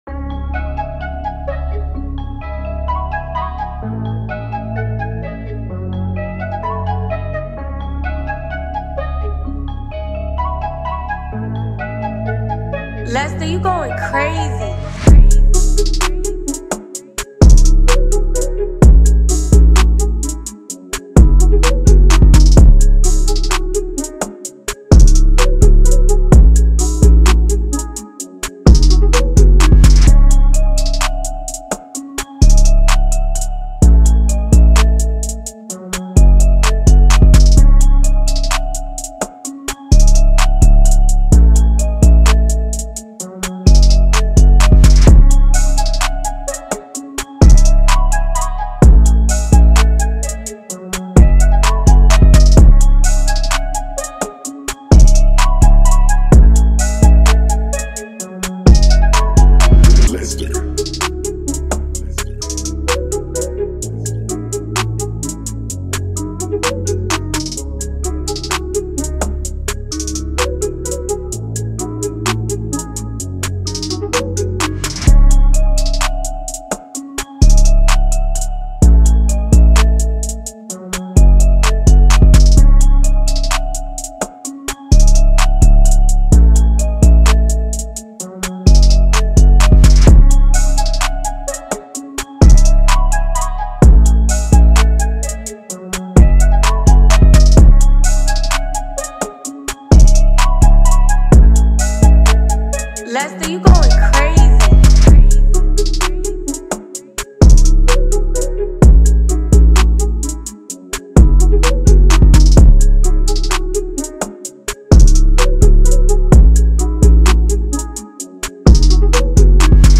ژانر : ترپ مود : مامبل | دیس تمپو : 128 تایپ